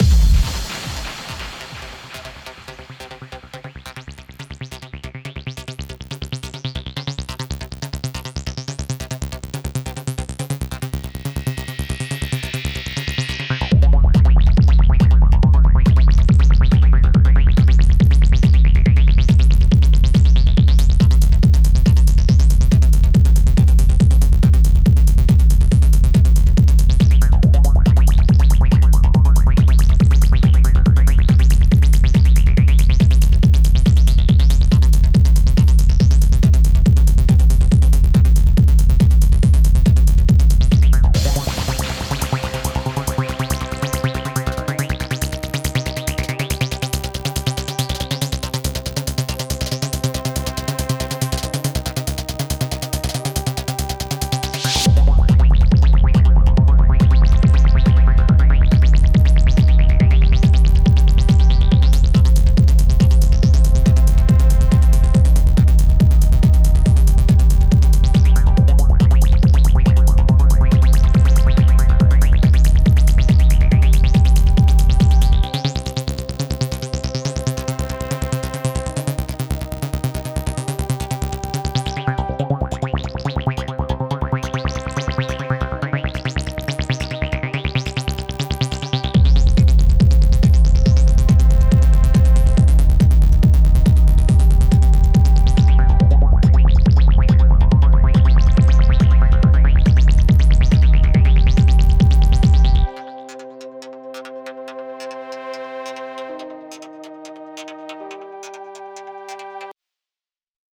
Der dazugehörige Techno-Rhythmus folgt einem straffen Viervierteltakt, ergänzt durch Claps, Snares und Hi-Hats.
Kombiniert mit einer einfachen, jedoch charakteristischen Bassdrum, im sogenannten 4-to-the-floor Rhythmus, wird das Publikum zum Tanzen angeregt.
Und wenn Sie dann einen Beat hinzufügen, haben Sie in nur wenigen Schritten einen eigenen Song im Acid Techno Stil produziert.